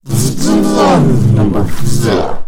声音 外星人 " 外星人科学家06
描述：在未来派和科幻游戏中使用的奇怪的昆虫般的外星人声音。
Tag: 未来派 gamedeveloping 语音 indiedev 游戏 视频游戏 科学家 谈话 gamedev 街机 游戏 外星人 声音 声乐 SFX 科幻 视频游戏 游戏 indiegamedev